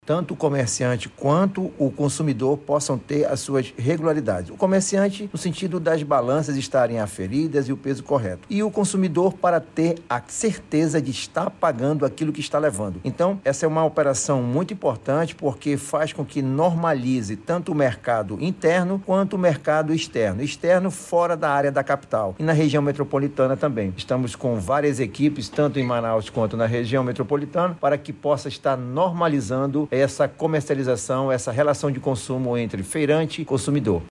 Na ocasião, os fiscais do Ipem Amazonas orientaram os feirantes sobre o uso correto das balanças, instrumentos regulamentados pelo Inmetro, conforme destaca o diretor-presidente da Instituição, Renato Marinho.